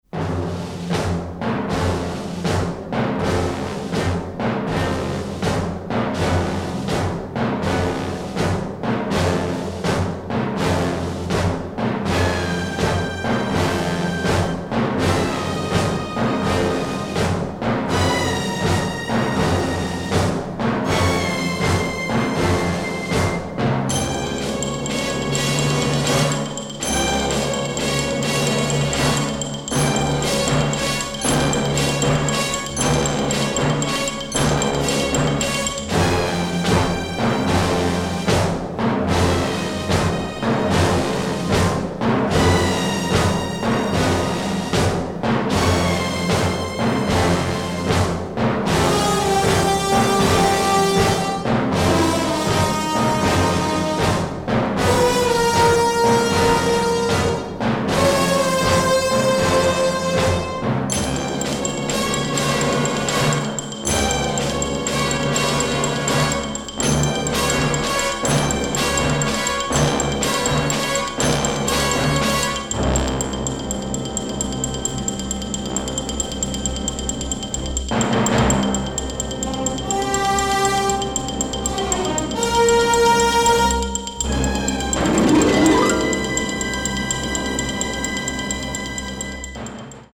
orchestral score